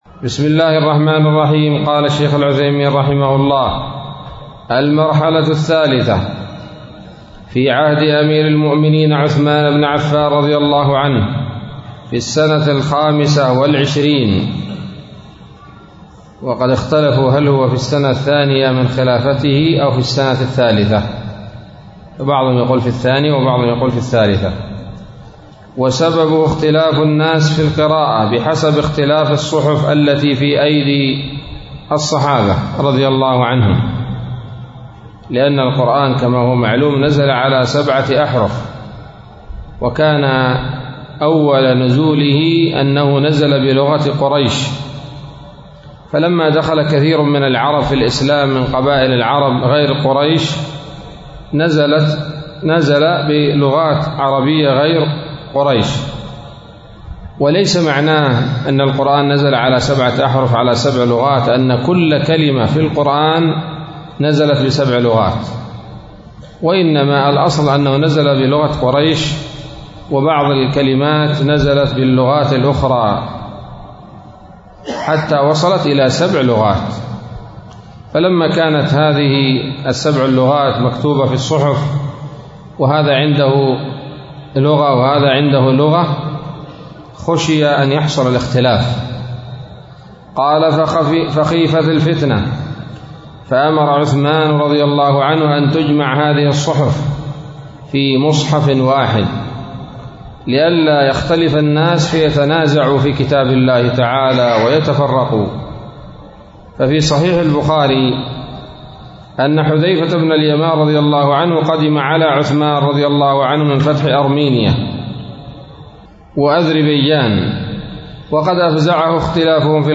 الدرس السادس عشر من أصول في التفسير للعلامة العثيمين رحمه الله تعالى